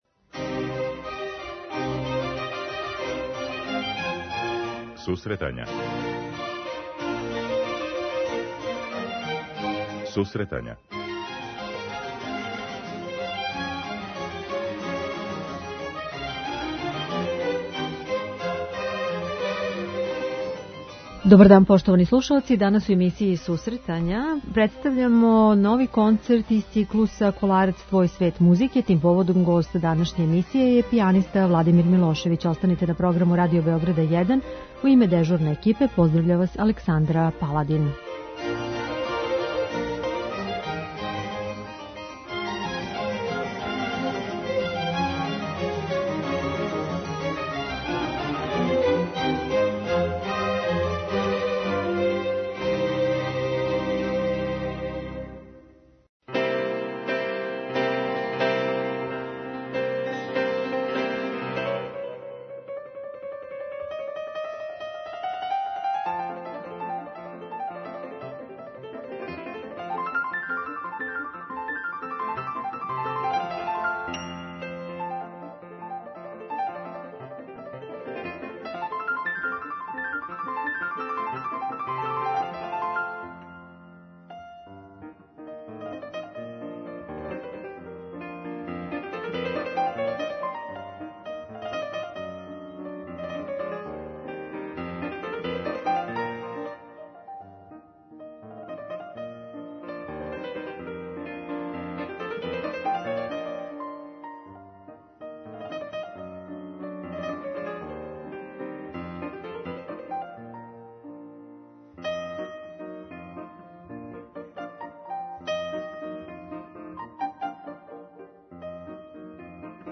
У делима која ће извести, пијанизму, публици и наступима, данас разговарамо са овим уметником.